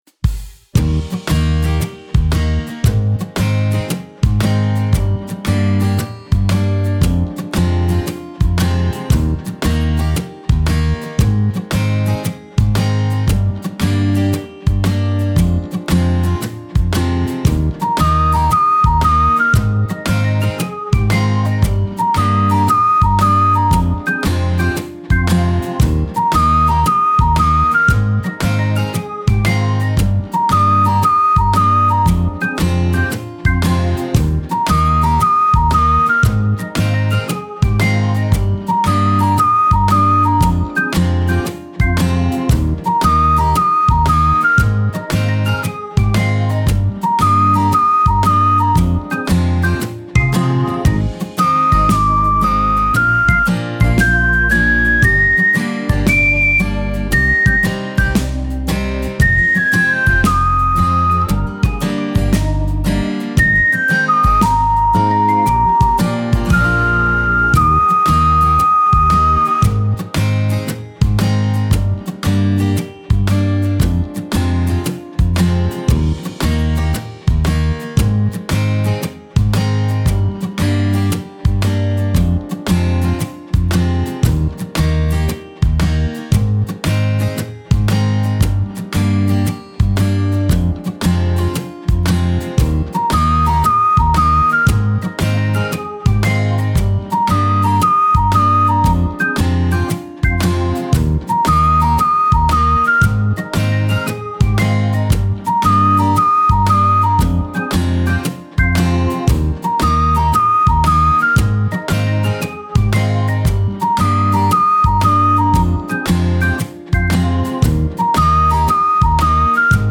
朝〜お昼の時間帯を感じるBGM
アコースティック 3:47